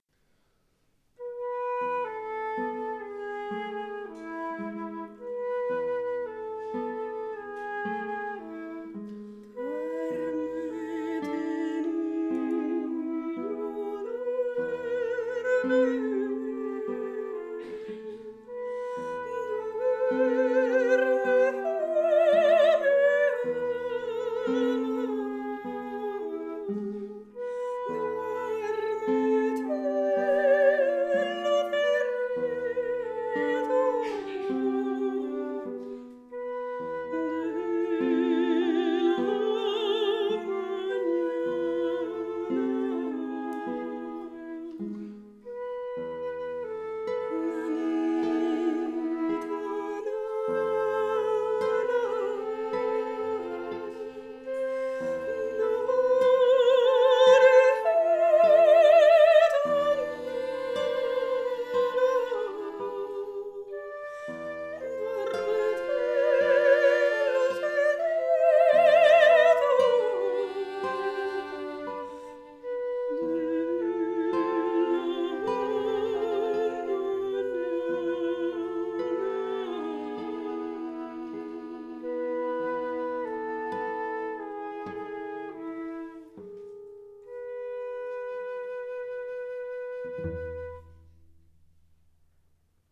mezzosoprano
flauto
violoncello
chitarra
Archivio Storico della Città di Torino
Live recording, Giugno 2007